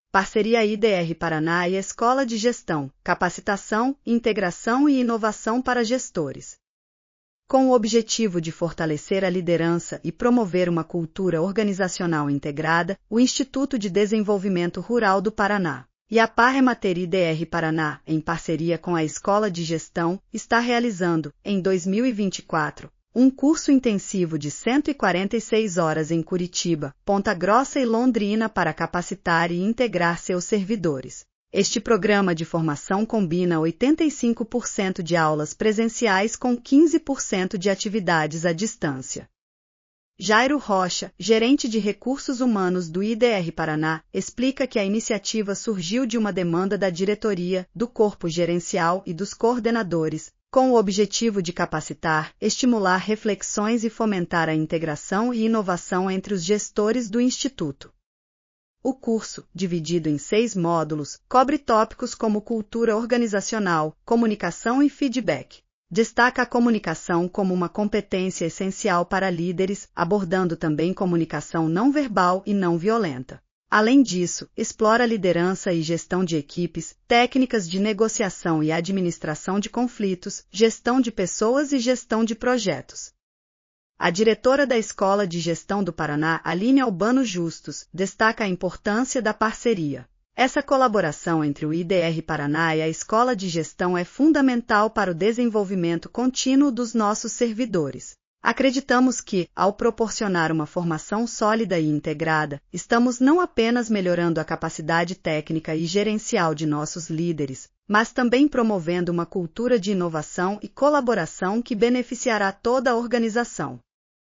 audio_noticia_parceria_idr.mp3